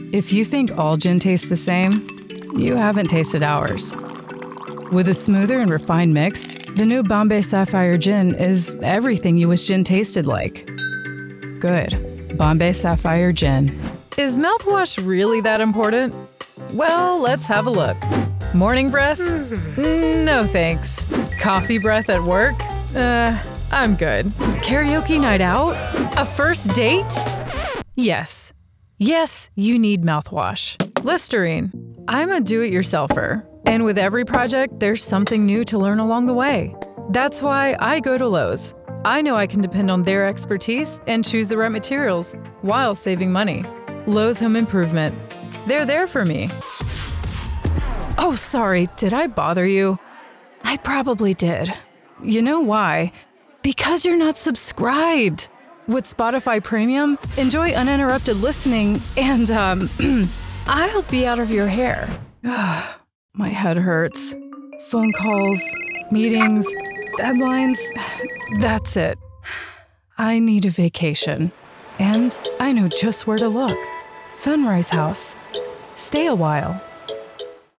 Looking for a rich, authentic and charming voice?
If you're looking for passion and sincerity, I have the voice for you.
Commercial Demo